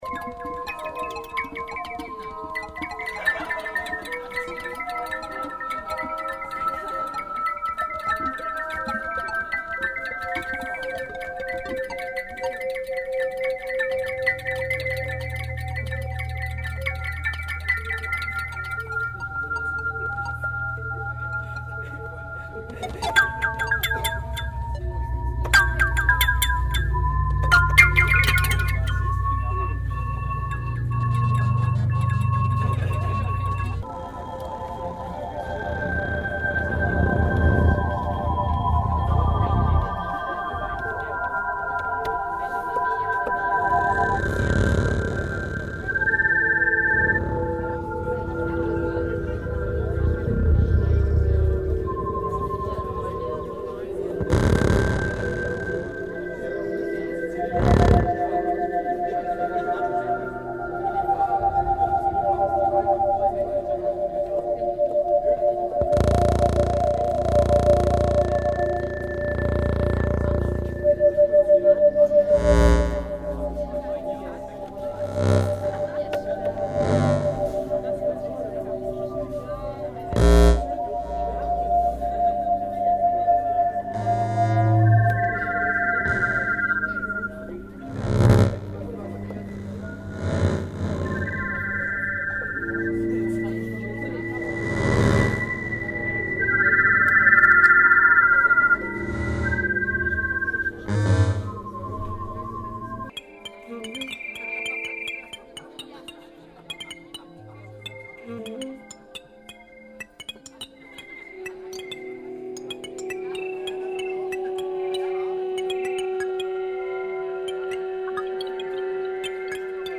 Wir sagten zu und warnten vor unserer experimentellen, elektronischen Musik - sie wollten das dennoch.
Durchaus eine Musik für Landschaften - merkwürdige Klänge, Glöckchengeläut, Luftklänge wie Gespinste, Musik wie für Tolkin, sehr klar im Klang wg. des freien Raumes (kein kastiger Innenraum-Klang), die Aufnahmen wie im besten Studio:
aus 5 verschiedenen Takes zusammengesetzt:
1. Alle Pd-Instrumente sehr komplex & Segelmast & Wolkendaten
2. Knarzgewitter & Grillen & Wolkendaten-Musik
3. Alt-Sax & Geklinker & Segelmast & Grillen & Synthi-Klänge
4. Uchiwadaiko-Pd-Lautklavier & Alt-Sax
5. Uchiwadaiko-Pd sehr tief & Impulsklavier & Wolkendaten-Musik & Alt-Sax
Die Aufnahme sollte wieder im Freien sein - die Akustik ist perfekt.
im Sonafe-Hof der Universität Ulm zwischen 19 und 22 Uhr